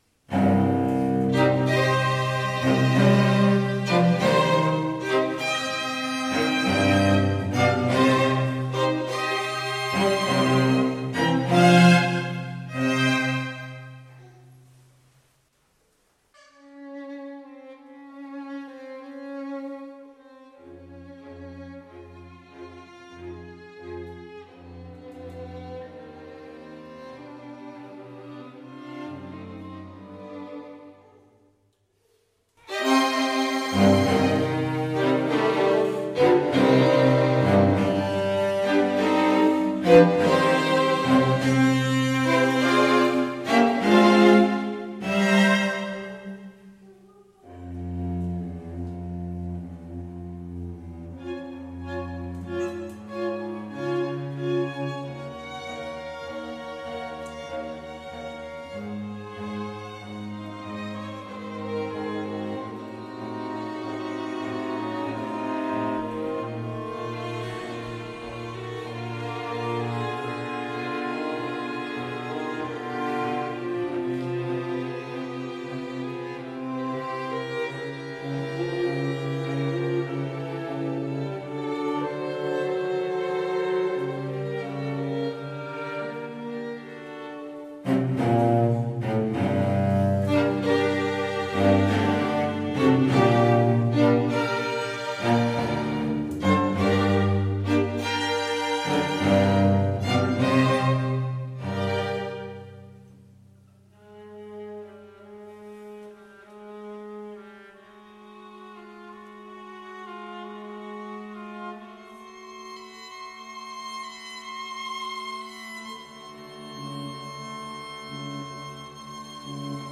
Free Sheet music for String Quartet
Violin 1Violin 2ViolaCello
Classical (View more Classical String Quartet Music)
Audio: Cuarteto Iranzo.